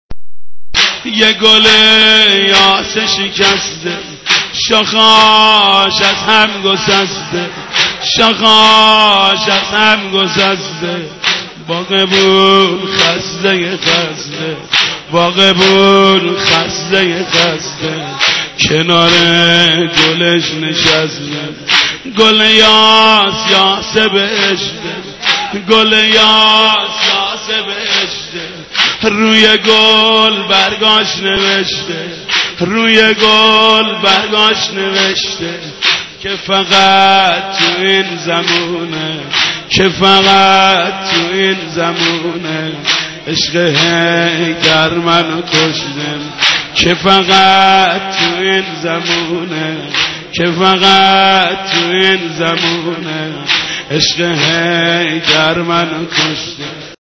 مدیحه‌‌ای از محمود کریمی در سوگ شهادت مادر سادات(س) صوت - تسنیم
خبرگزاری تسنیم: محمود کریمی از مداحان برجسته کشور است که همواره با صدای دلنشین خود در خدمت اهل بیت(ع) بوده است.
دو فایل صوتی از مداحی او به مناسبت شهادت حضرت فاطمه زهرا(س) به شرح زیر است: انتهای پیام/